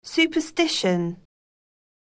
영국[sjùːpərstíʃən]